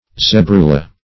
Search Result for " zebrula" : The Collaborative International Dictionary of English v.0.48: Zebrula \Ze"bru*la\, Zebrule \Ze"brule\, n. A cross between a male zebra and a female horse.